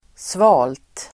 Uttal: [sva:lt]